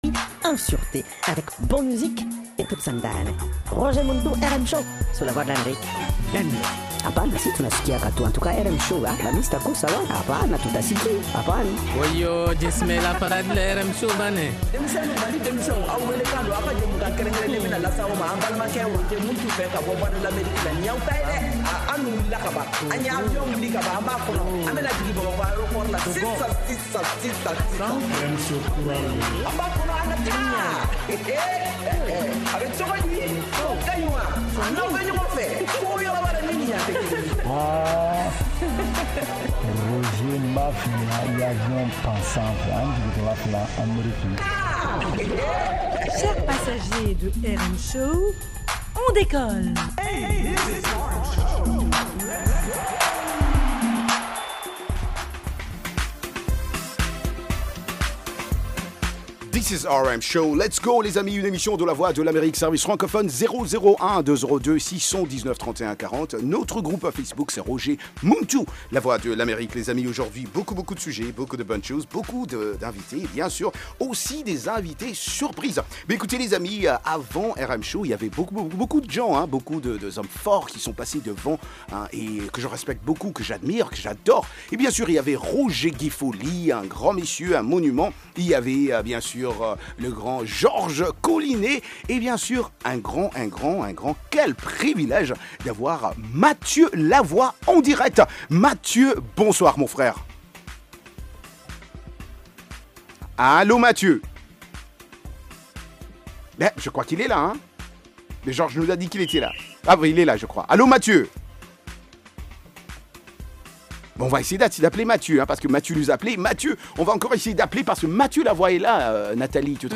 une sélection spéciale de musique malienne et internationale. Participez aussi dans Micro-Mali pour debattre des sujets socio-culturels.